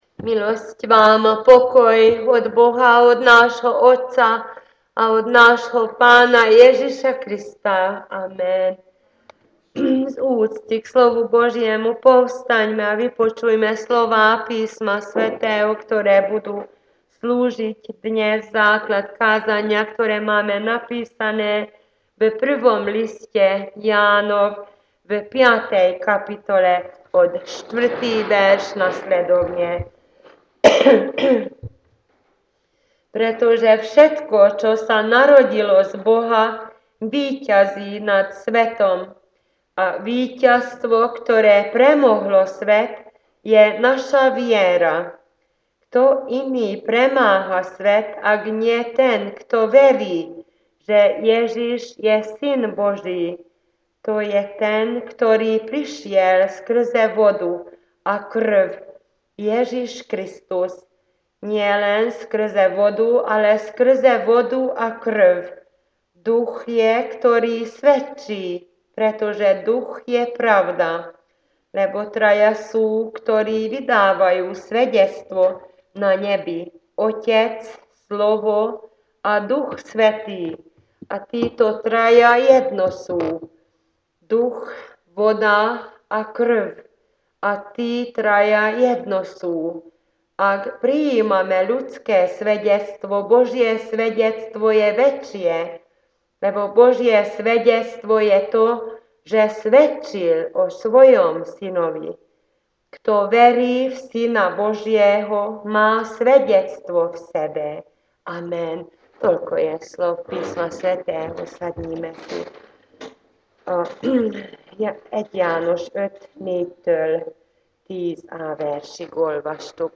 igehirdetése